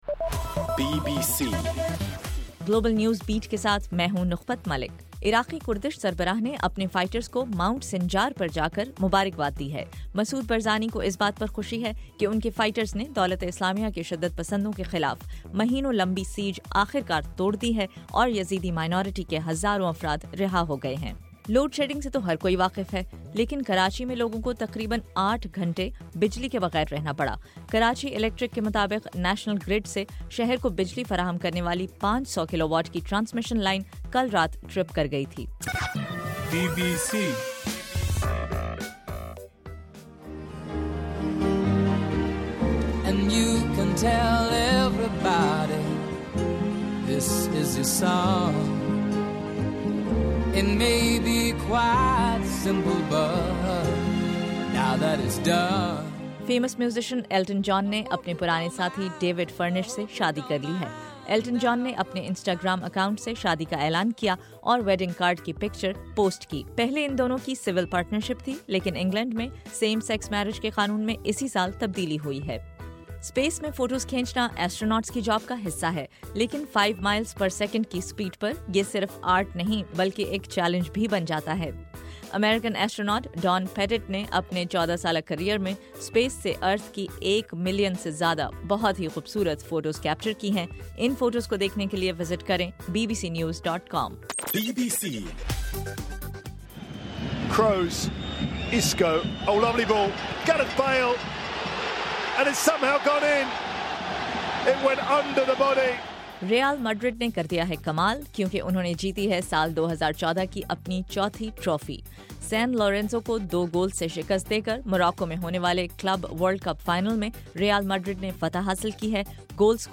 دسمبر21: رات 9 بجے کا گلوبل نیوز بیٹ بُلیٹن